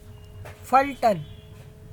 pronunciation) is a city, tehsil and municipal council in the Satara district in the Indian state of Maharashtra.